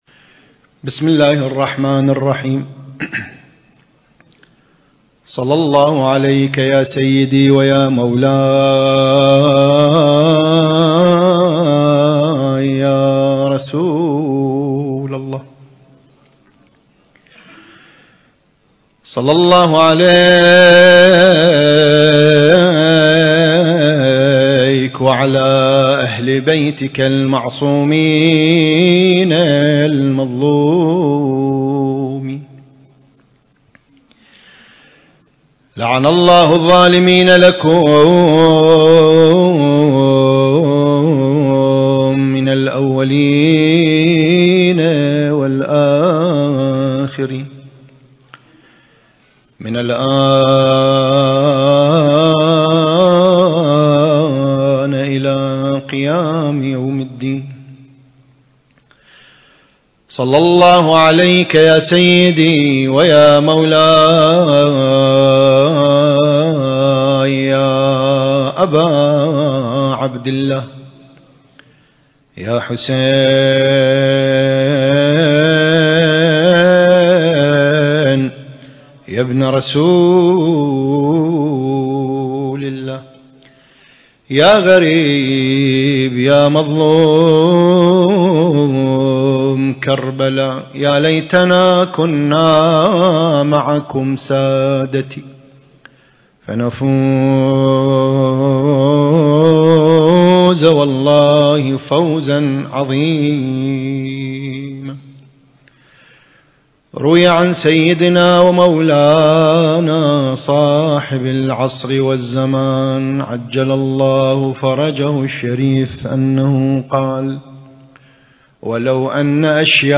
المكان: موكب النجف الأشرف/ قم المقدسة التاريخ: 1444 للهجرة